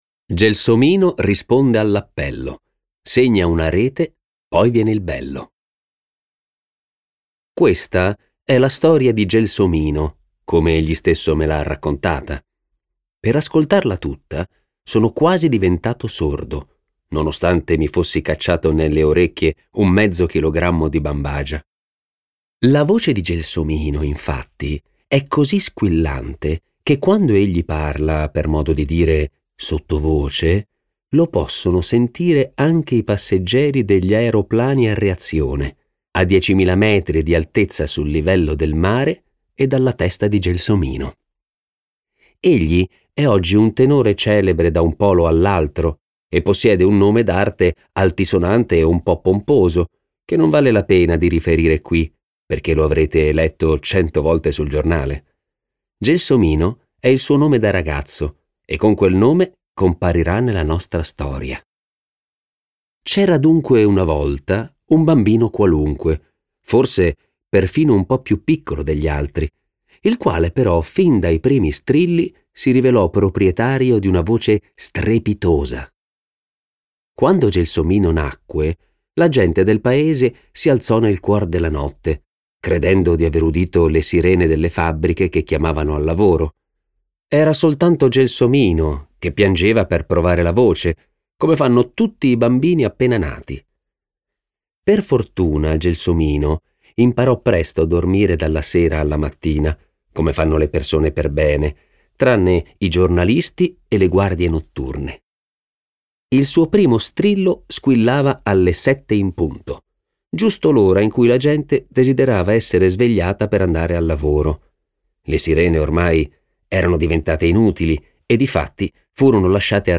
letto da Giorgio Marchesi
Versione audiolibro integrale